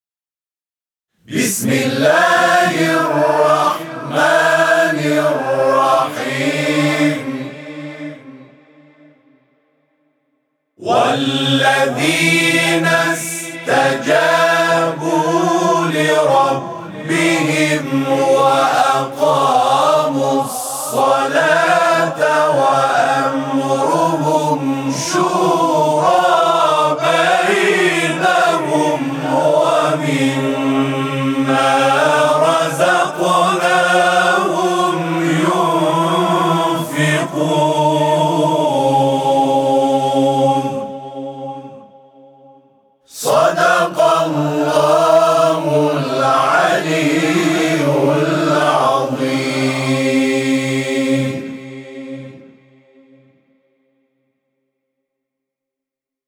صوت همخوانی آیه 38 سوره شوری از سوی گروه تواشیح «محمد رسول‌الله(ص)»